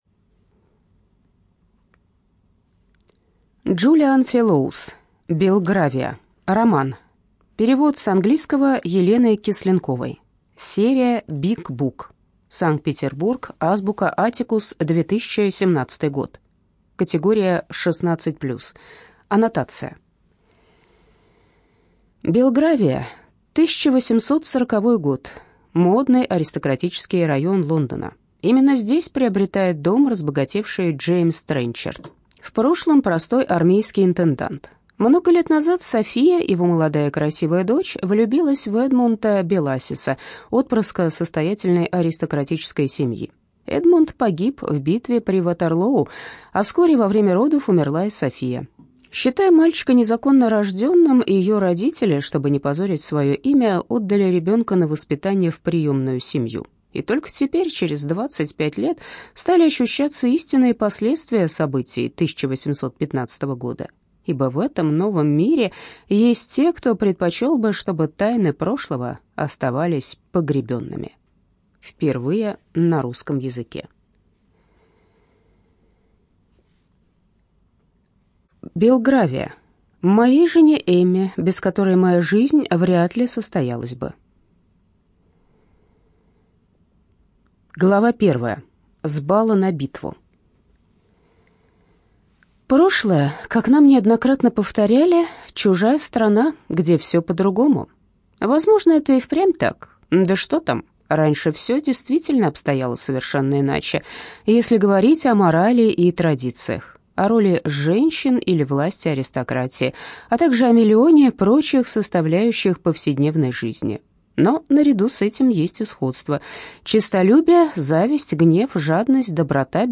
ЖанрИсторическая проза
Студия звукозаписиЛогосвос